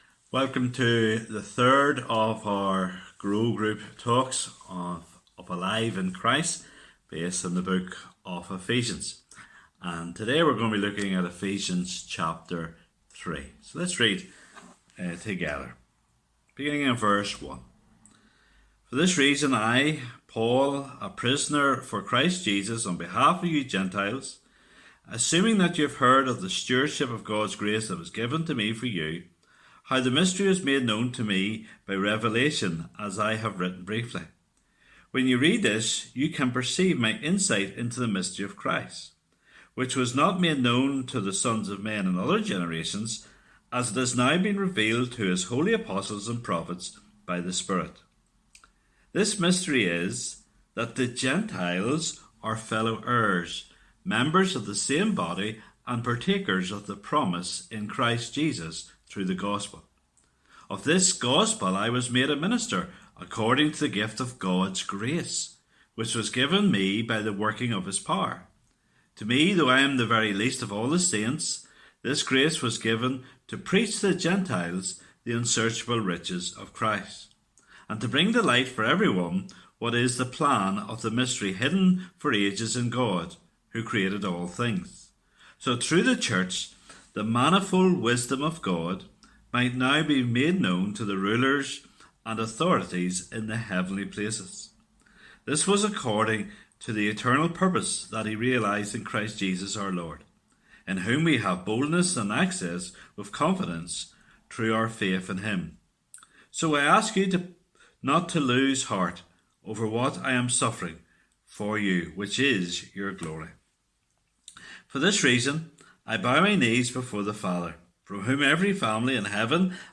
Listen to sermon audio. Ephesians 3 The Mystery of the Gospel Revealed